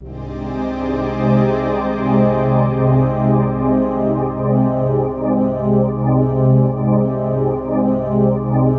Index of /90_sSampleCDs/USB Soundscan vol.13 - Ethereal Atmosphere [AKAI] 1CD/Partition A/02-AMBIANT B
AMBIANT07.-R.wav